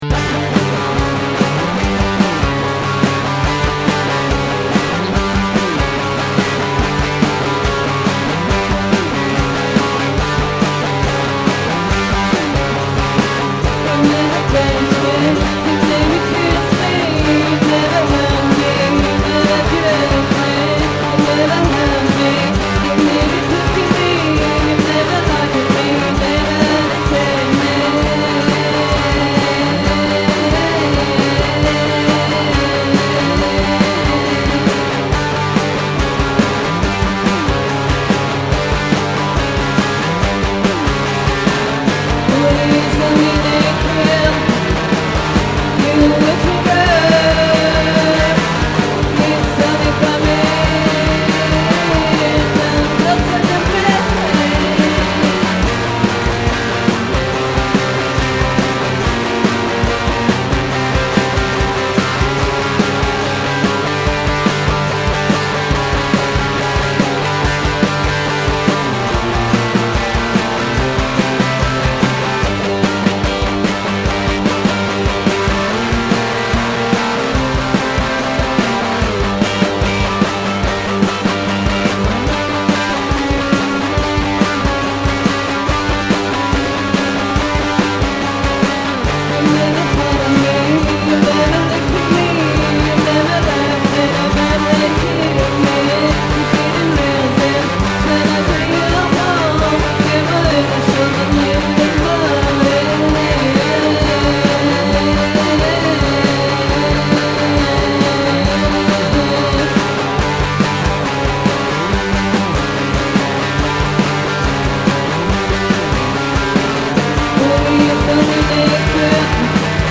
guitar
bass & drum machine